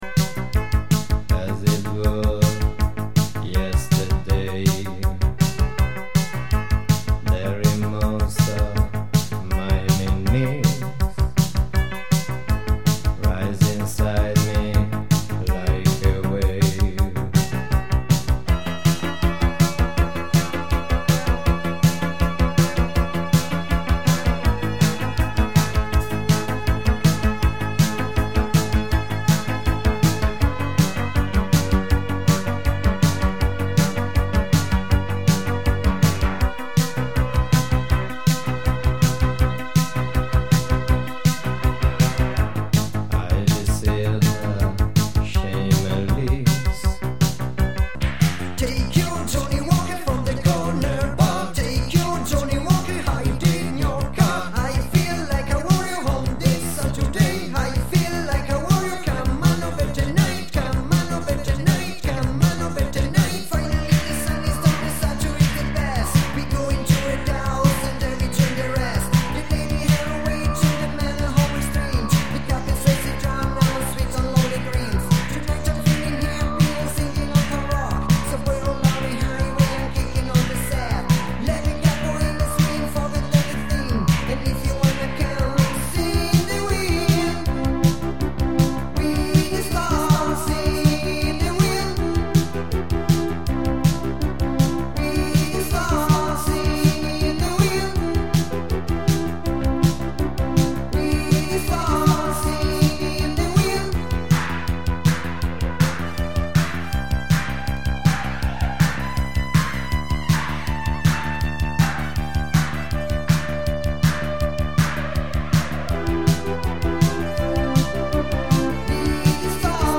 Genre Italo Disco